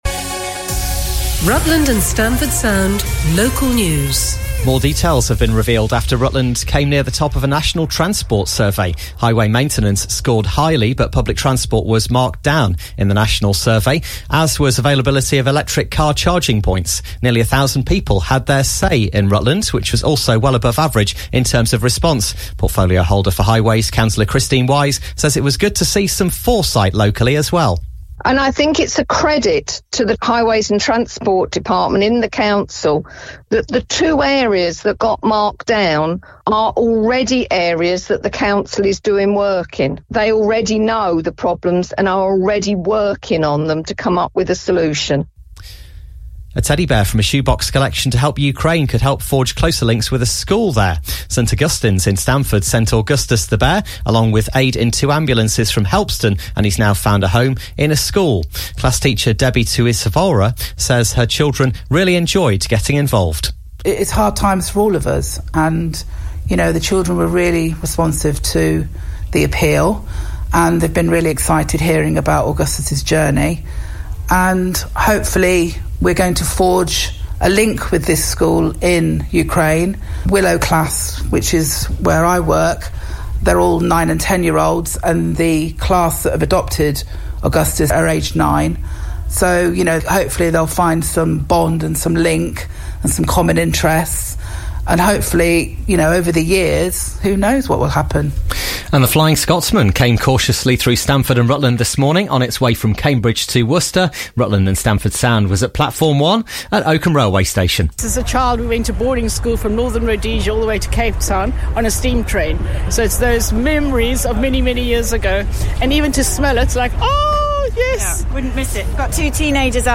and this morning's news bulletin and weather